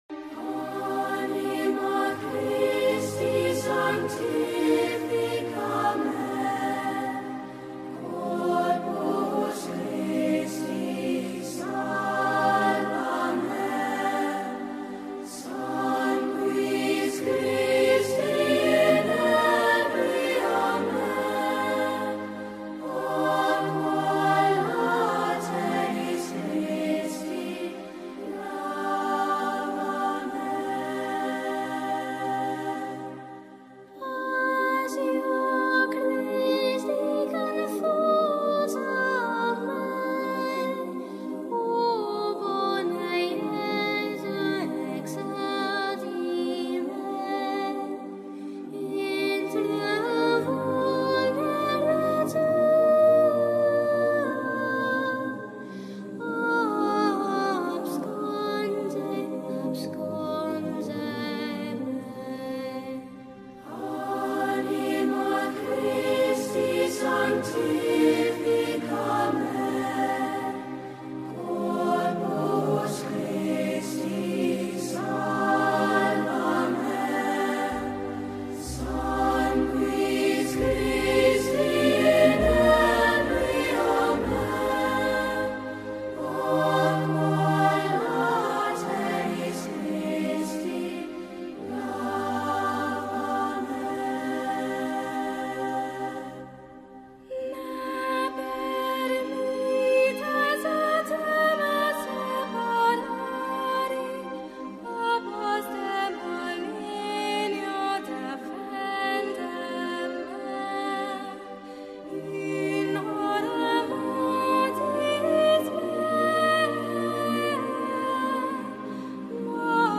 medieval chant